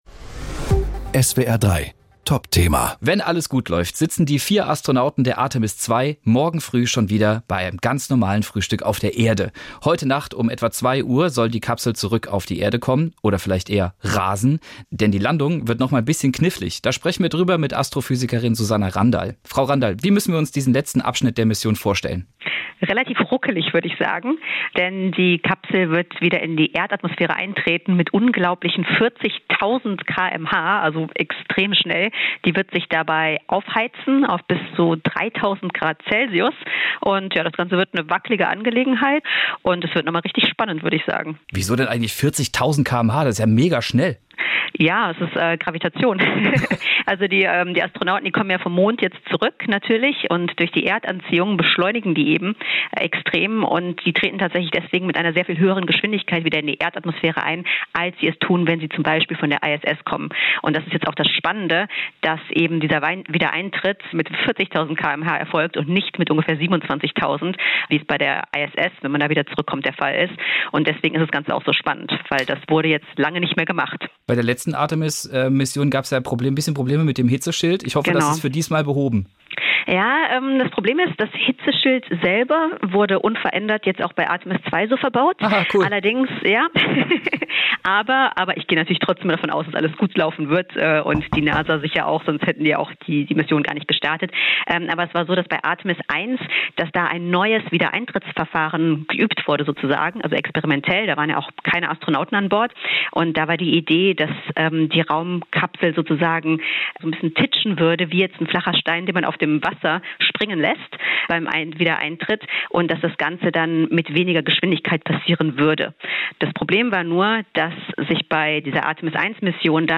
Nachrichten
Da sprechen wir drüber mit Astrophysikerin